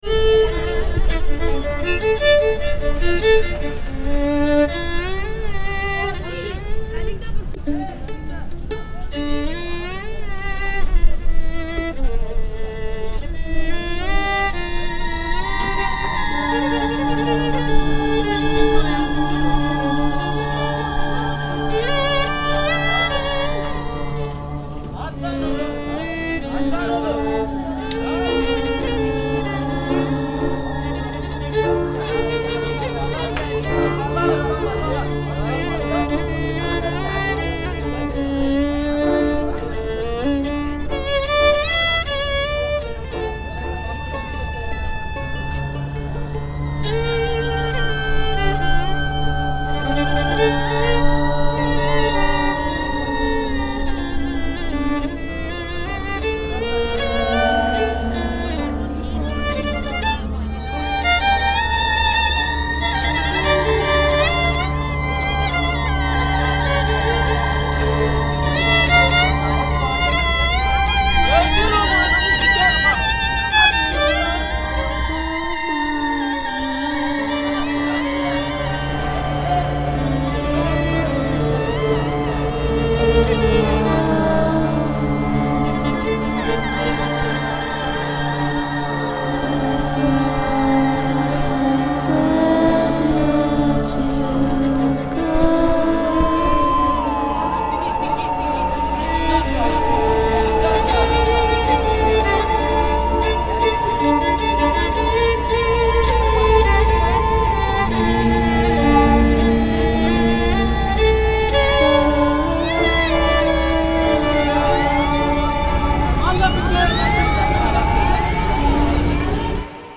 Istanbul violinist reimagined